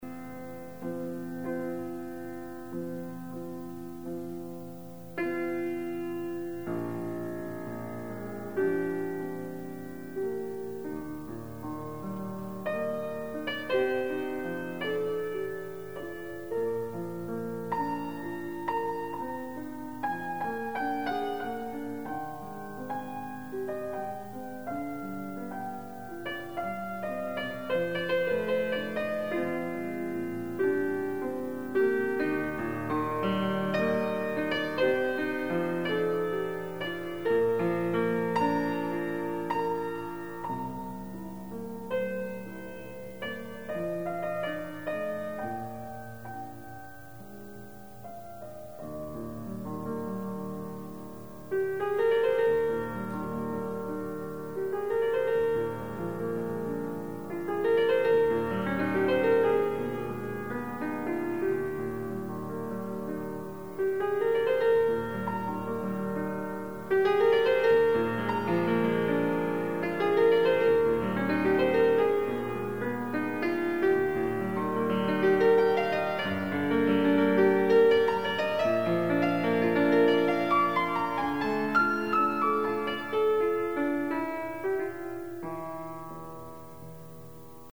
Additional Date(s)Recorded September 11, 1977 in the Ed Landreth Hall, Texas Christian University, Fort Worth, Texas
Sonatas (Piano)
Short audio samples from performance